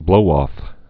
(blōôf, -ŏf)